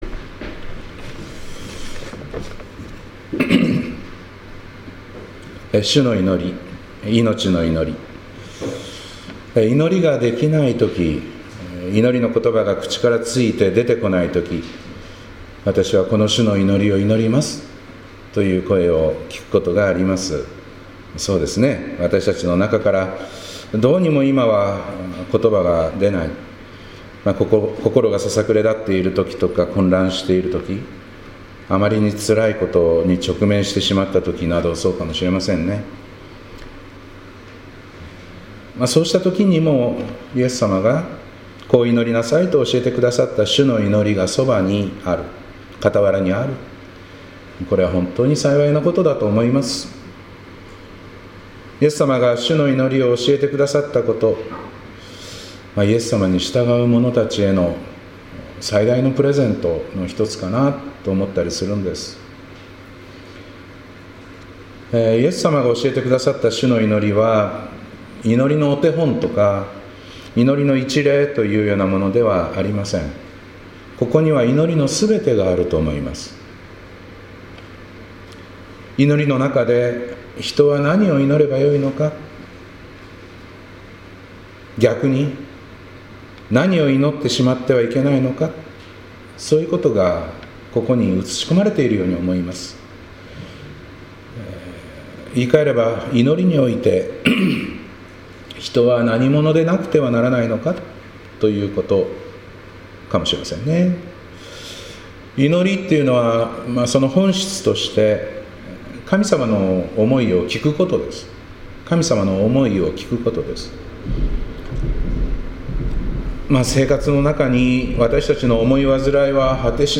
2025年1月26日礼拝「主の祈り、いのちの祈り」